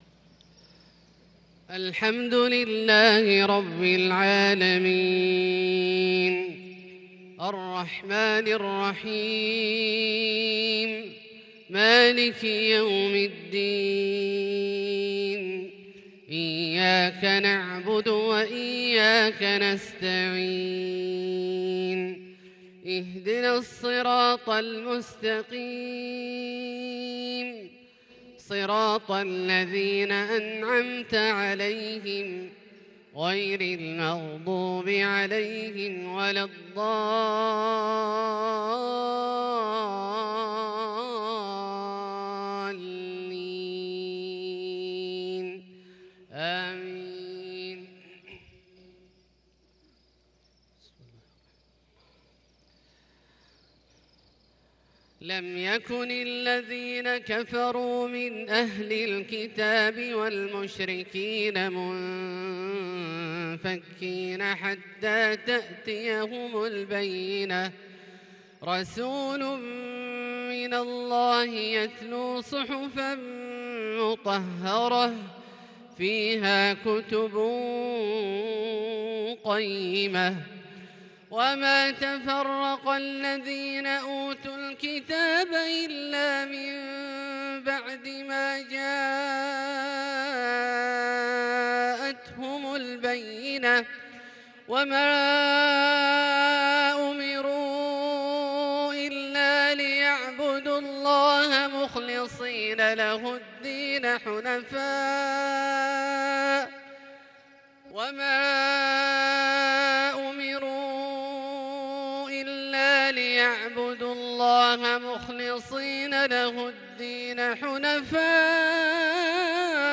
مغرب الثلاثاء 6 شوال 1442هـ سورتي البينة والزلزلة > ١٤٤٢ هـ > الفروض - تلاوات عبدالله الجهني